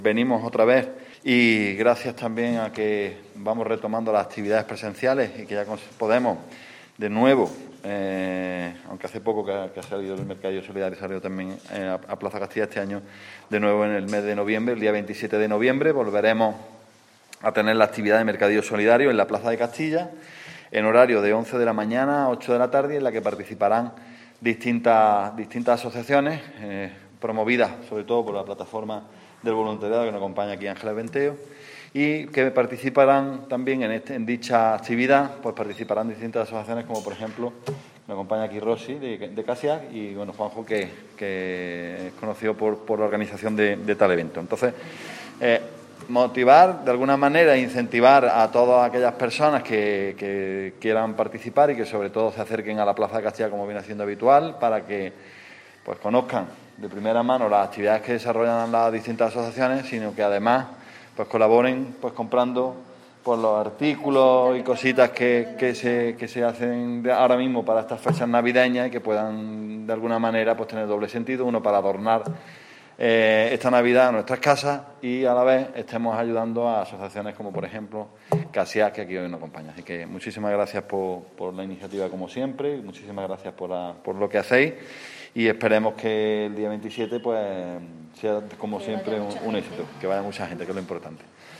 El teniente de alcalde delegado de Programas Sociales y Cooperación Ciudadana, Alberto Arana, ha anunciado hoy en rueda de prensa el desarrollo de una nueva edición del Mercadillo Solidario.
Cortes de voz